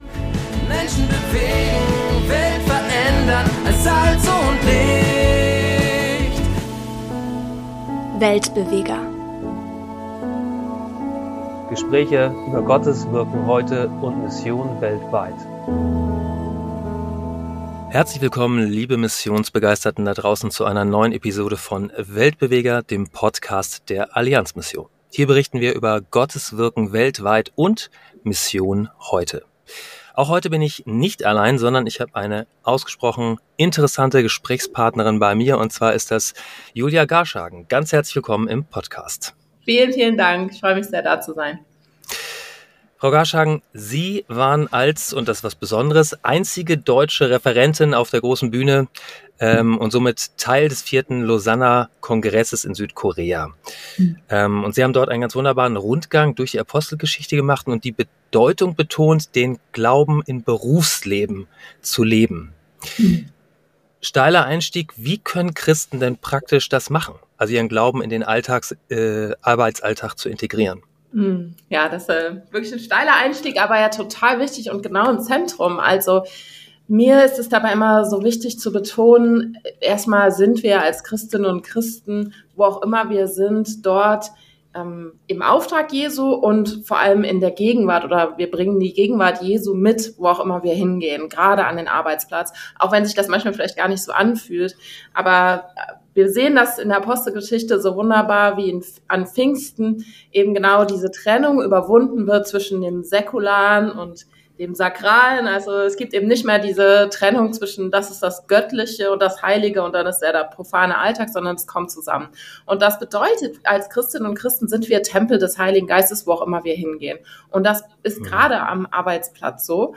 Im Gespräch betont die Bedeutung, als Christ im Berufsleben präsent zu sein und die Gegenwart Gottes in alle Aspekte des Lebens zu bringen. Sie diskutiert die Herausforderungen, die Christen im modernen Arbeitsumfeld begegnen, und die Rolle des Pontus-Instituts, das Brücken zwischen Wissenschaft, Kultur und Glauben baut.